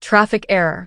audio_traffic_error.wav